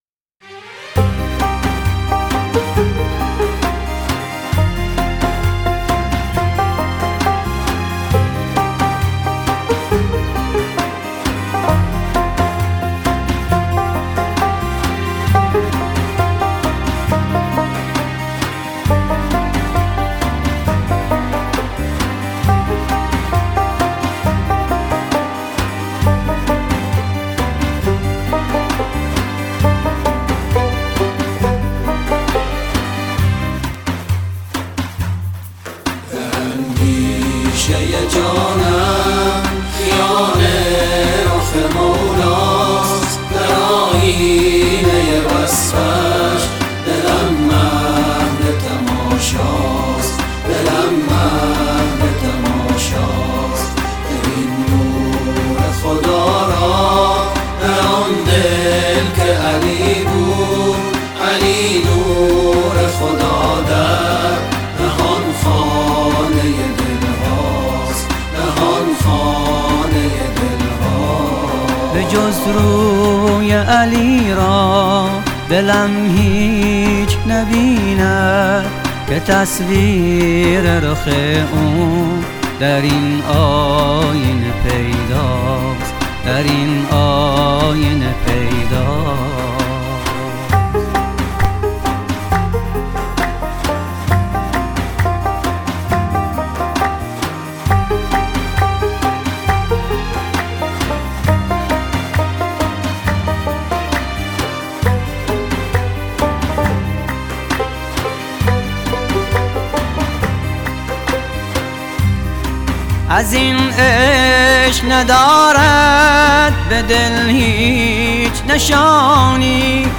قطعه موسیقایی و آیینی
برچسب ها: میلاد حضرت علی (ع) ، ماه رجب ، شعر علوی ، شعر آیینی ، موسیقی مذهبی